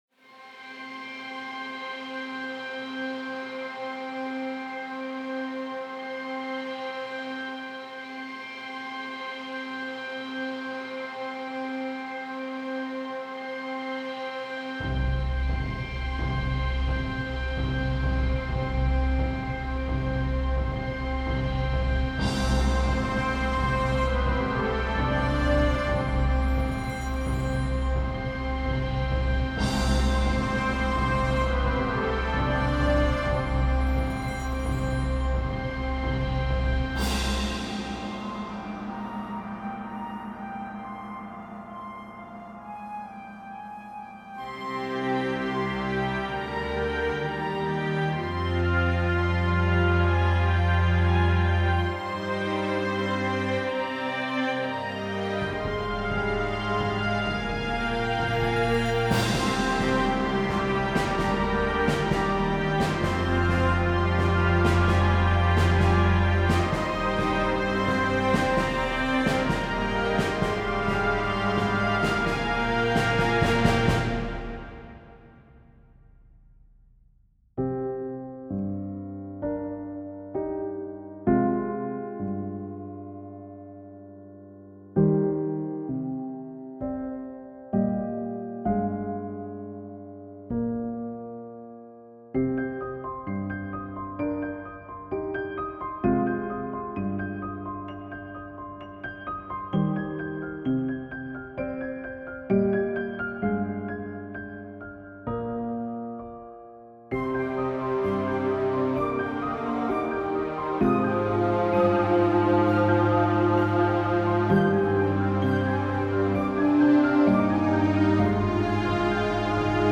New Sci-fi Inspired Piece - Orchestral and Large Ensemble - Young Composers Music Forum
This one I aimed for less spooky, eerie and more sci-fi. With some big, grand visuals and the voids of space represented in the sections of this piece.